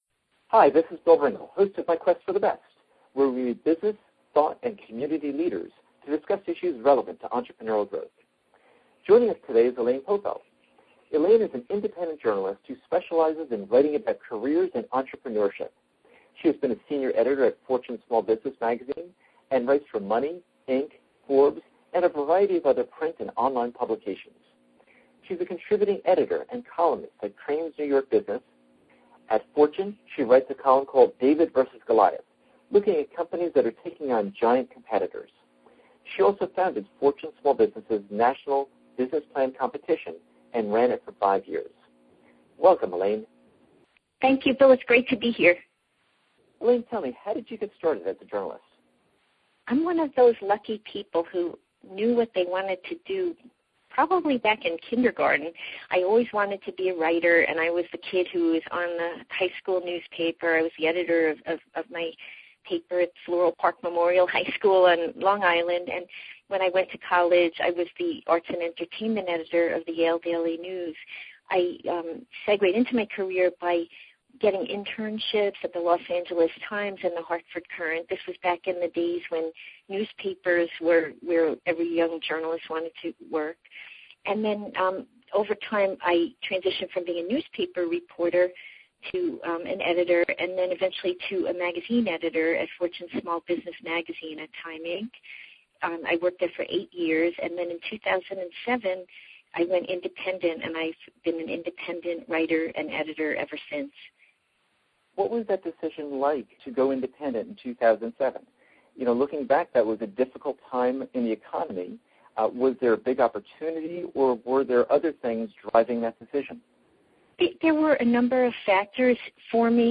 Listen to this interview to learn: Keys to building great relationships with clients How the criteria you use for evaluating opportunities changes as you change your business vision